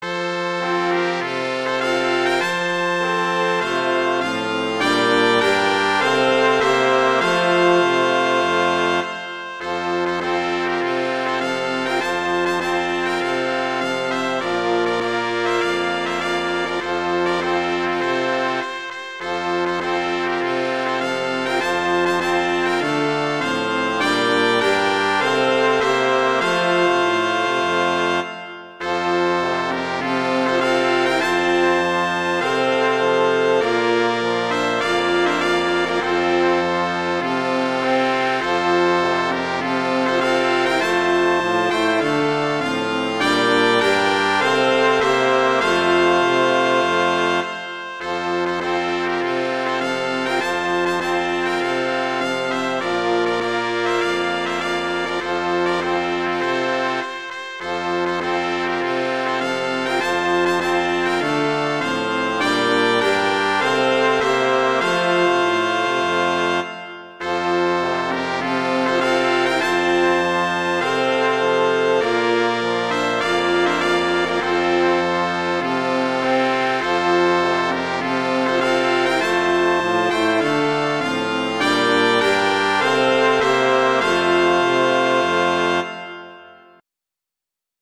arrangements for brass quintet